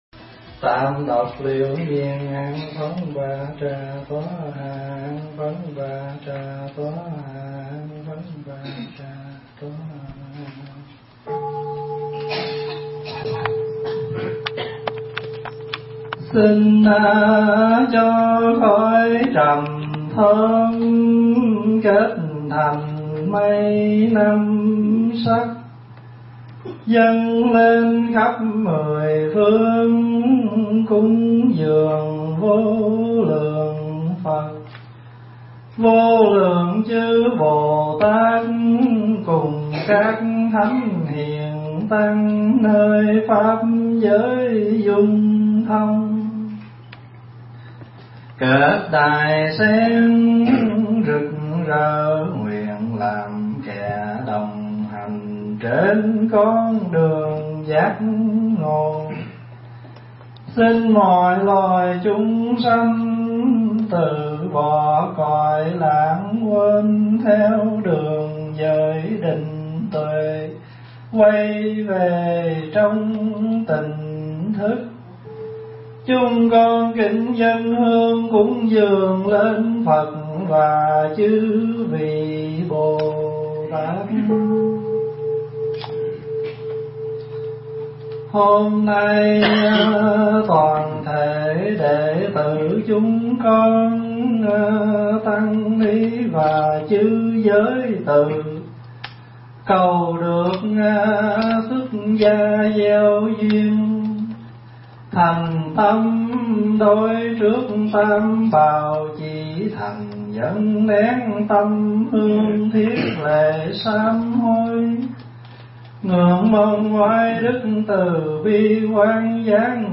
thuyết giảng tại Tu Viện Tây Thiên, Canada trong Khóa Tu Xuất Gia Gieo Duyên Kỳ 11